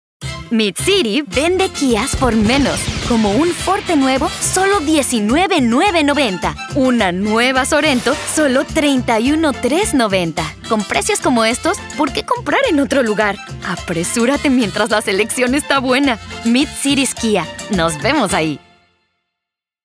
Professional Spanish voice over for local automotive ads, dealership promos, and national campaigns.
Dealership Promotion